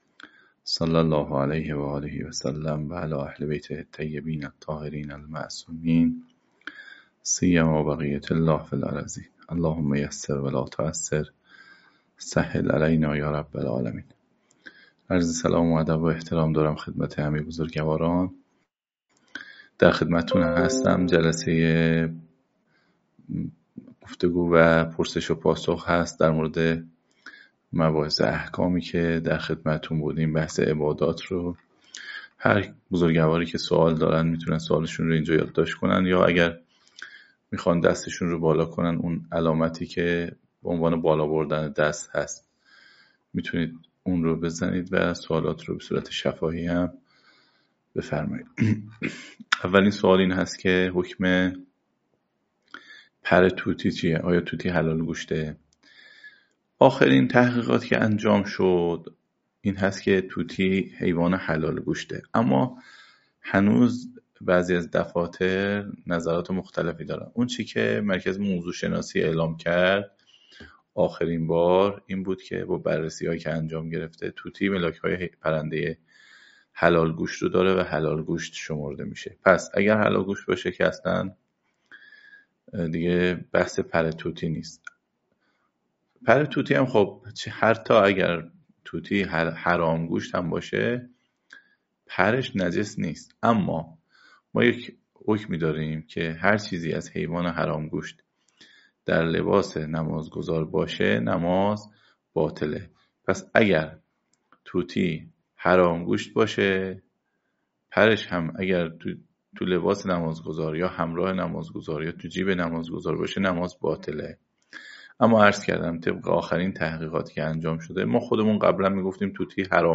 پایه‌ نیایش (احکام عبادات) - جلسه-پرسش-و-پاسخ